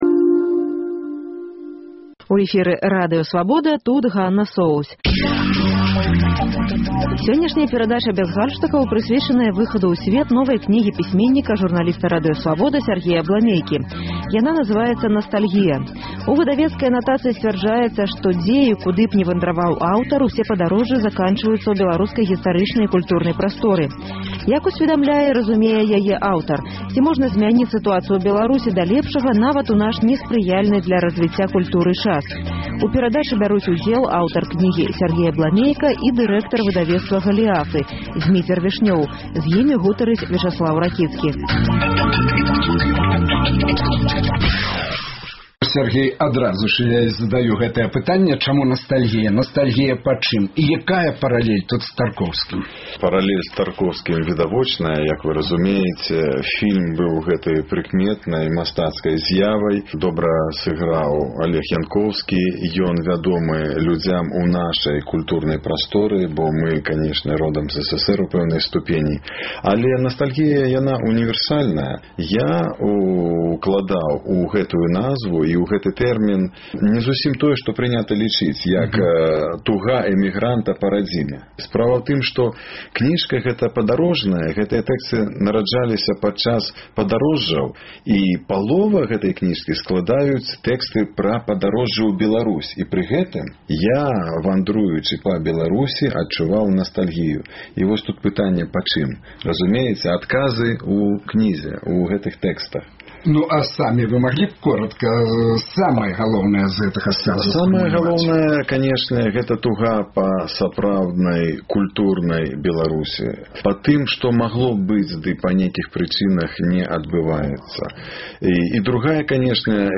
У тэлестудыі Радыё Свабода пісьменьнік